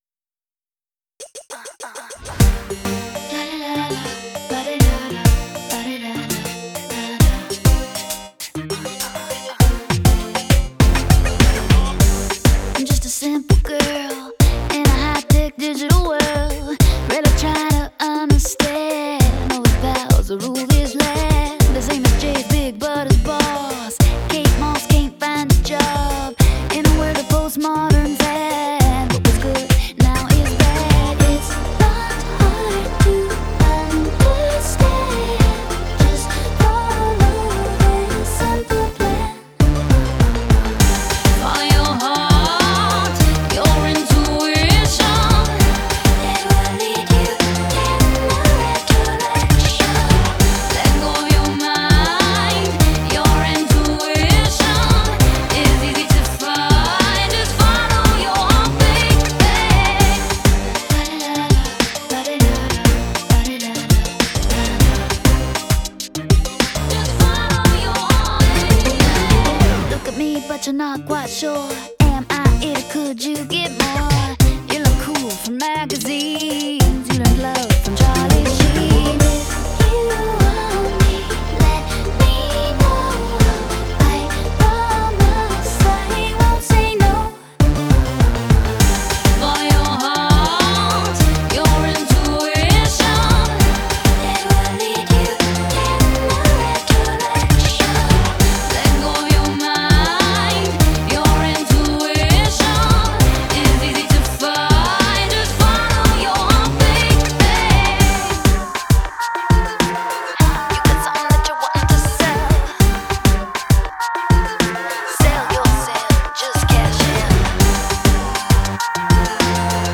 а ремикс добавляет современное звучание и танцевальный ритм